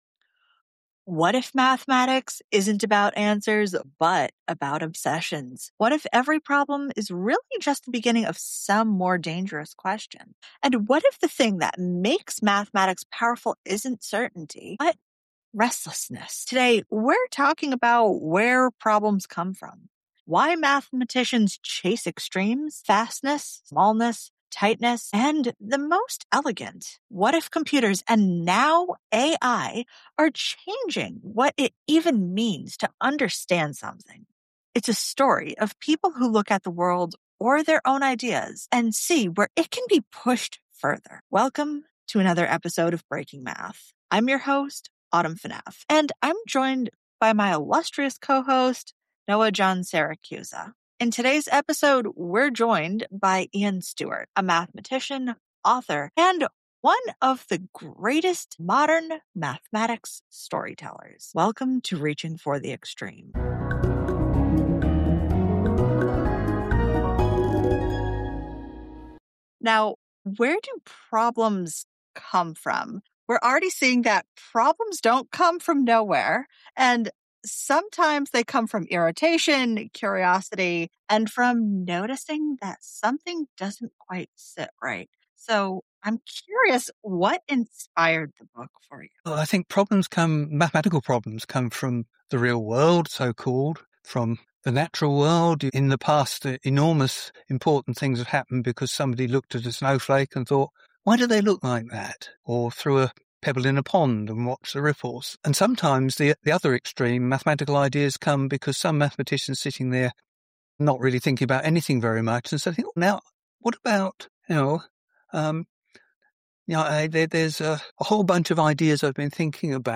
In this conversation, Ian Stewart discusses the nature of mathematical inquiry, the motivations behind problem-solving in mathematics, and the importance of storytelling in making math relatable. He explores the relationship between nature and mathematics, emphasizing how patterns in nature inspire mathematical concepts. Stewart also addresses the role of AI in mathematical discovery and the importance of choosing meaningful problems to work on.